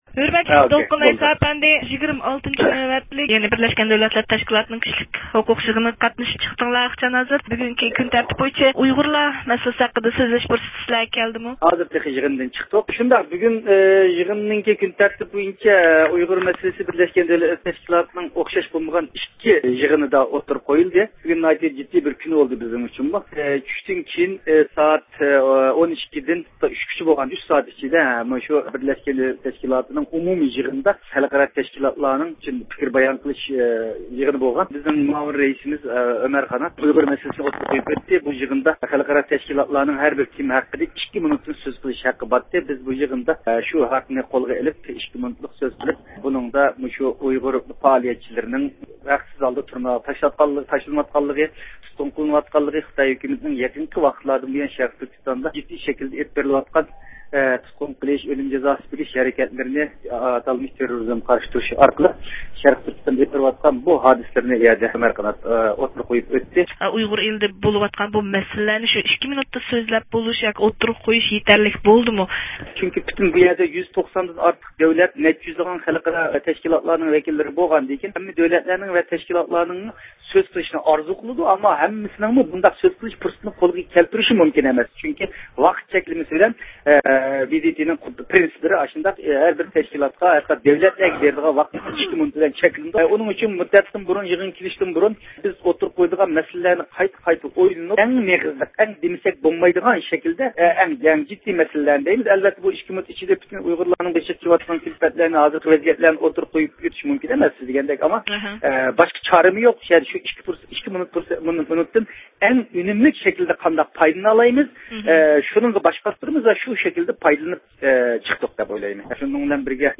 دۇنيا ئۇيغۇر قۇرۇلتىيى ۋەكىللىرىدىن ئىجرائىيە كومىتېت رەئىسى دولقۇن ئەيسا ئەپەندى زىيارىتىمىزنى قوبۇل قىلىپ، بىرلەشكەن دۆلەتلەر تەشكىلاتىنىڭ بۈگۈنكى ئومۇمىي يىغىنىدا دۇنيا ئۇيغۇر قۇرۇلتىيىنىڭ ئەڭ زور ئۇتۇقىنىڭ، ئۇيغۇر مەسىلىسىنى ئاڭلىتىش داۋامىدا خىتاي دىپلوماتلىرىنىڭ توسقۇنلۇقىغا ئۇچرىماي تۇرۇپ، خىتاينىڭ ئۇيغۇرلارغا قاراتقان كىشىلىك ھوقۇق مەسىلىلىرىنى ئاڭلىتىپ ئۆزىنىڭ مەيدانىنى دەخلى-تەرۇزسىز ئىپادىلەش پۇرسىتى بولغانلىقى ئىكەنلىكىنى ئوتتۇرىغا قويدى.